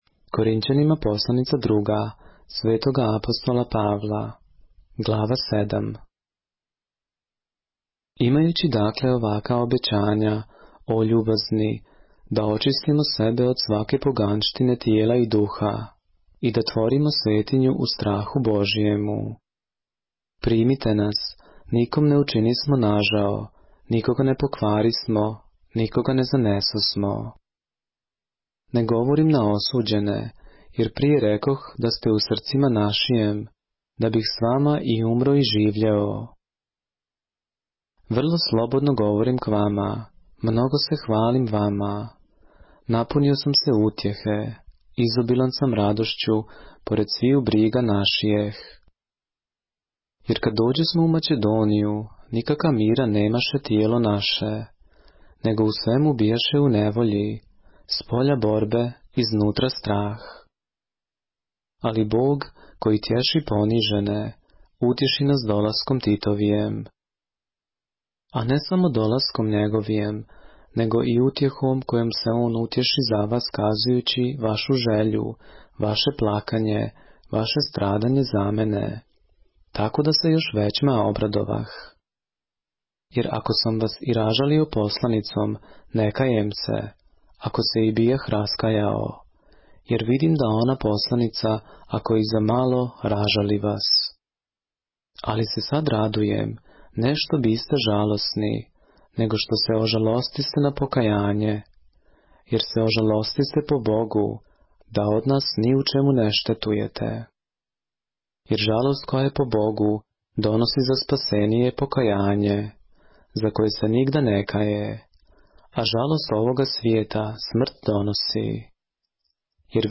поглавље српске Библије - са аудио нарације - 2 Corinthians, chapter 7 of the Holy Bible in the Serbian language